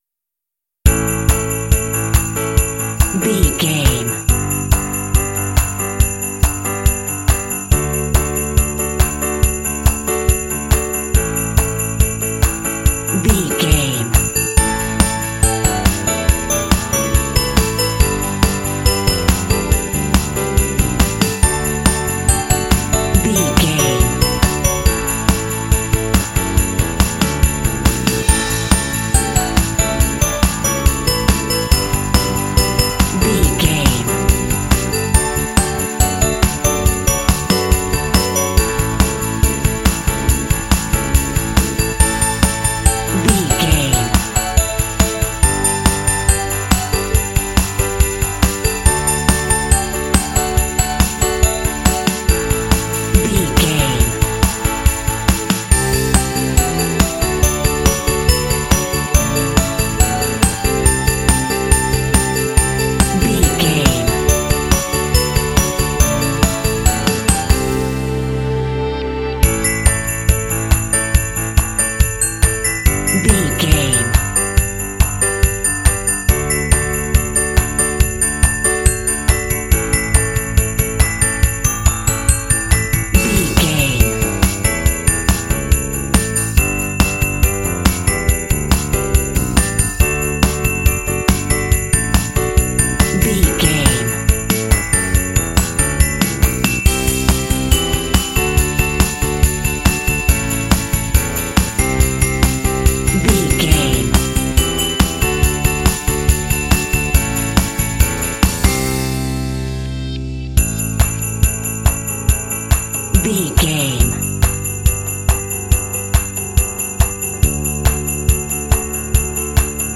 Ionian/Major
happy
uplifting
bouncy
festive
drums
bass guitar
electric guitar
contemporary underscore